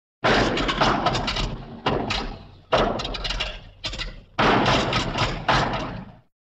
Радио и рингтоны » Звуки » Шум транспорта » Звуки Porsche
Звуки Porsche
На этой странице собраны лучшие звуки Porsche: рев двигателей, работа турбин и другие аудиоэффекты, которые передают дух легендарного бренда.